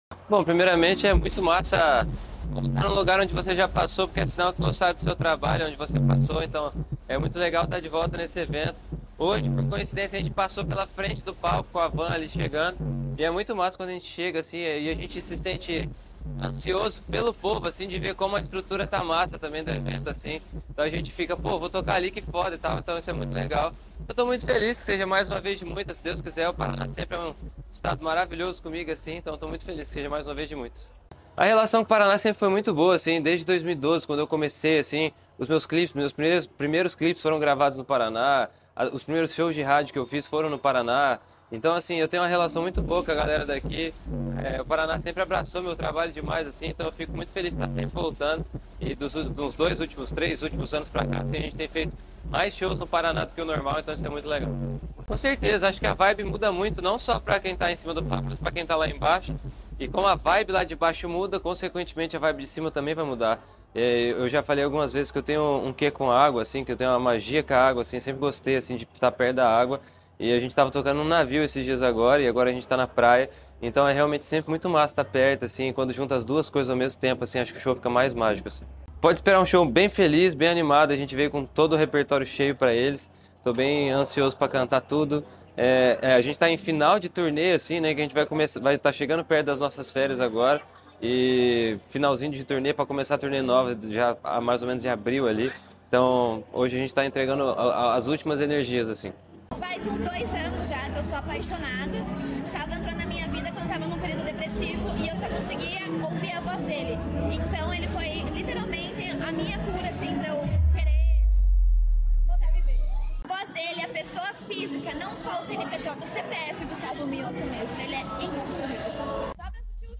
Sonora do cantor Gustavo Mioto sobre o show em em Pontal do Paraná pelo Verão Maior Paraná